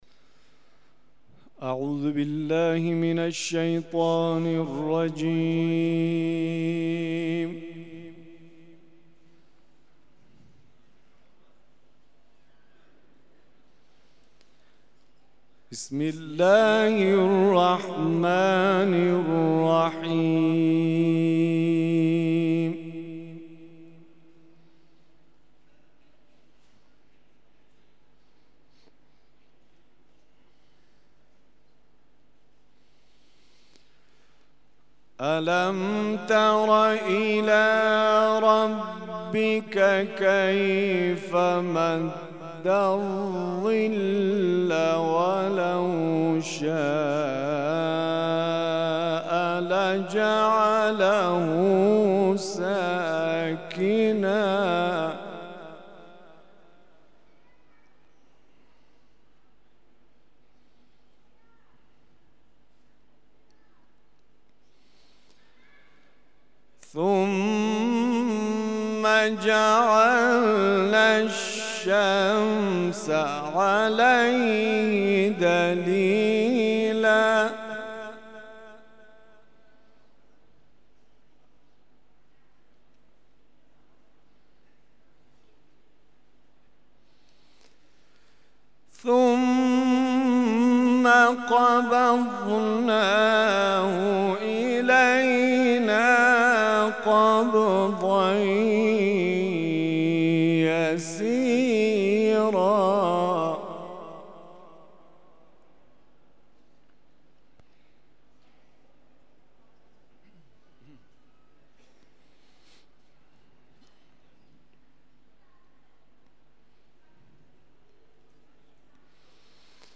تلاوت مغرب
تلاوت قرآن کریم